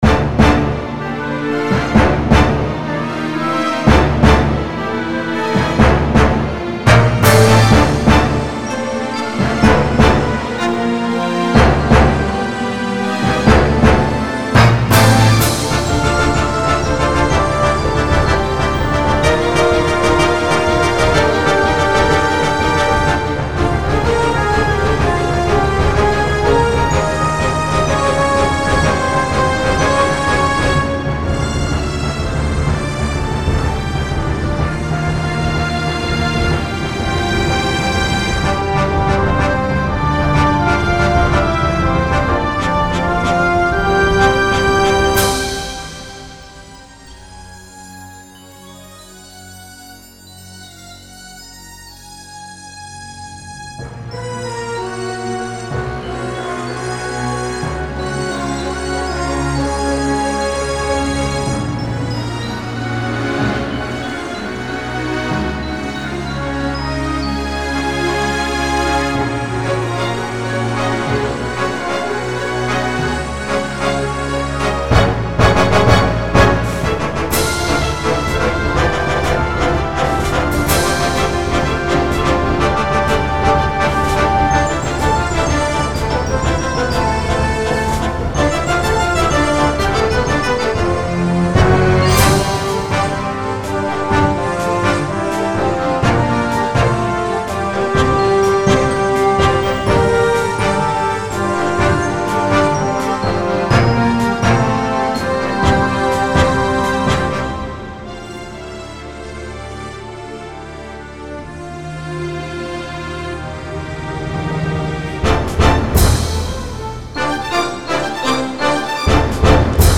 Original mp3 music
An orchestral soundtrack piece.